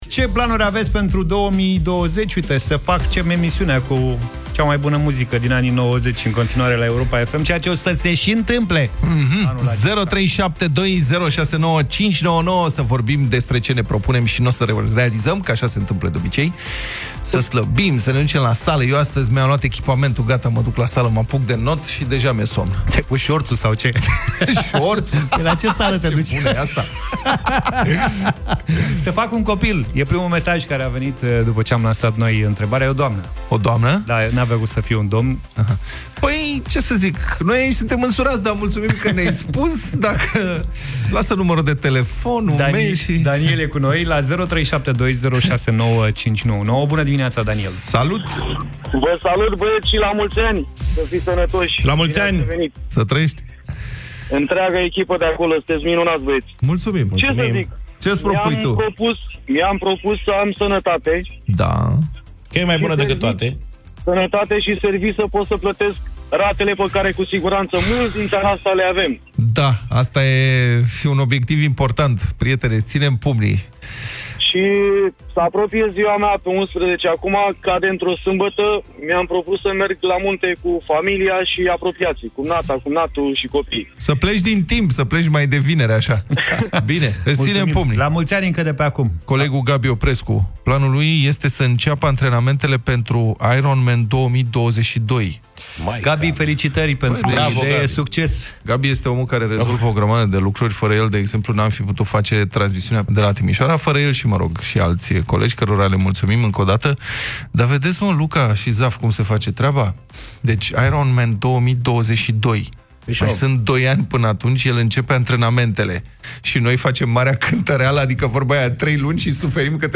Dezbatere în Deșteptarea: Cu ce planuri v-ați început anul? – AUDIO